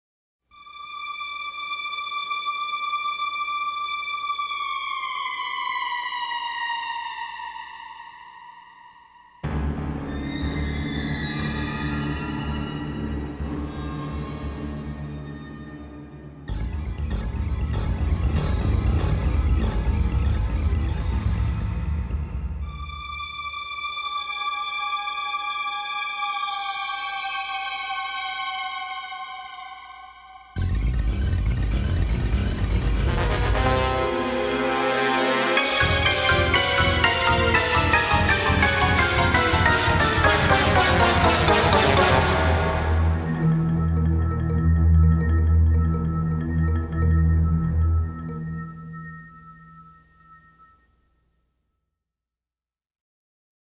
sci-fi soundtrack